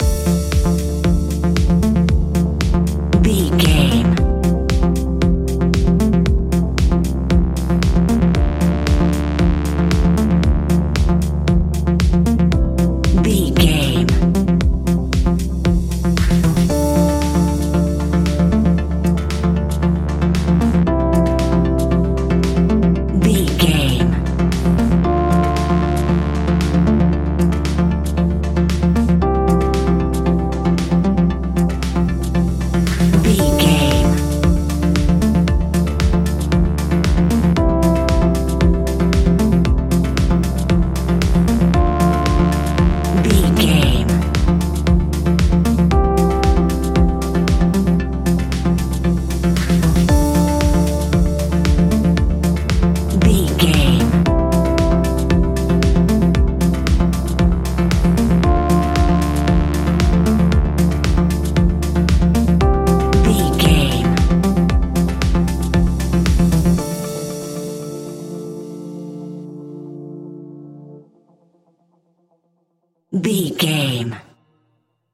Aeolian/Minor
groovy
uplifting
futuristic
driving
energetic
repetitive
synthesiser
drums
drum machine
electric piano
dance
synthwave
synth leads
synth bass